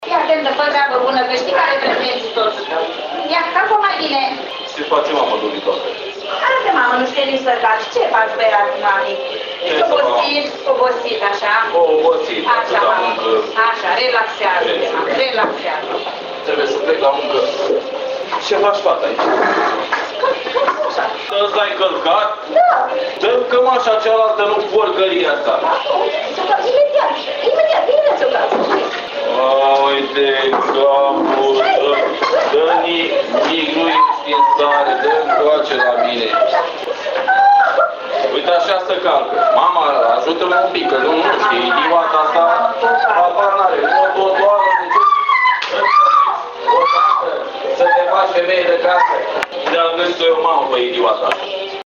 Actori într-o scenetă din păcate comună multora dintre familii au fost 4 cadre didactice de la Şcoala 2 din Ţăndărei. Dincolo de opiniile specialiştilor, secvenţele au fost discutate cu invitaţii din public.